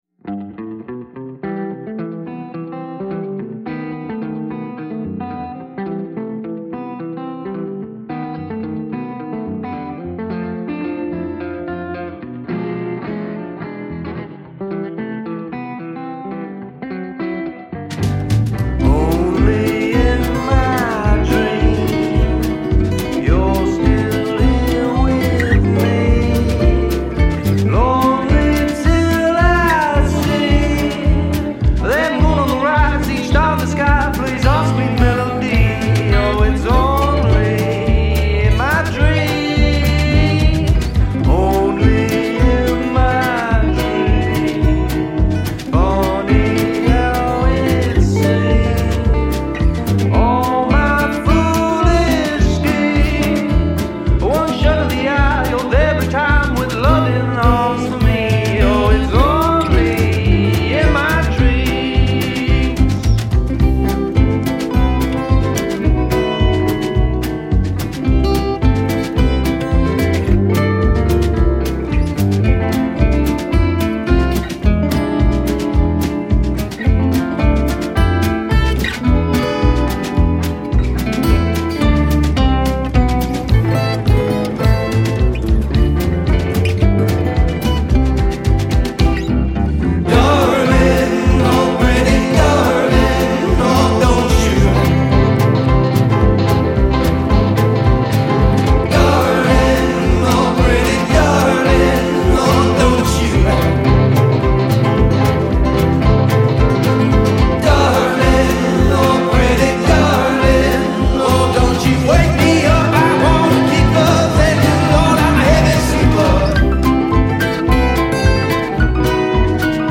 канадская рок-группа